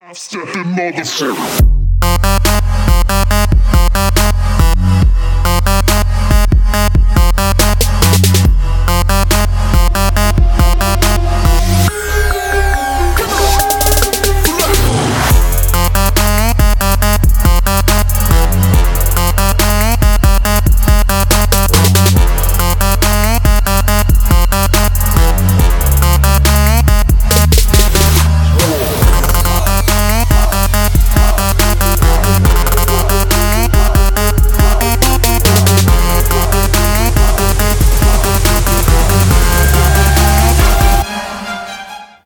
ритмичные
громкие
мощные басы
Trap
качающие
Bass
Trapstep